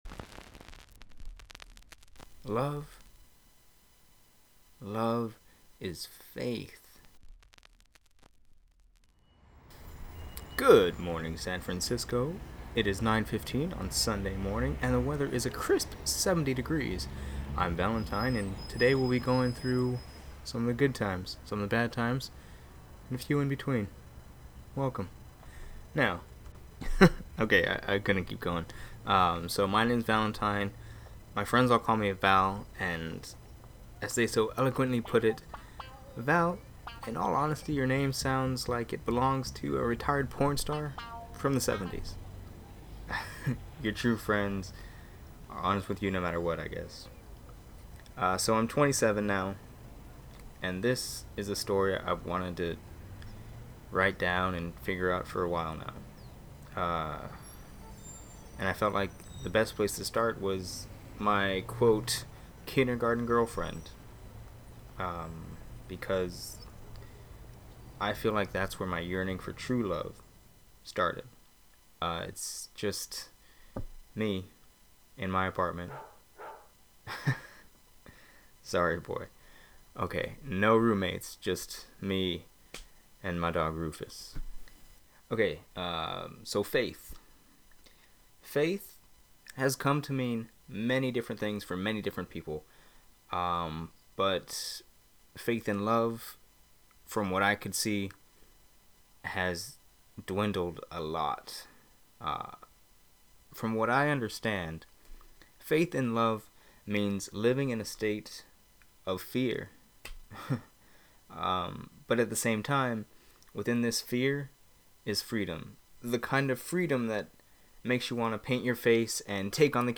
Again, for best results listen with earbuds or headphones.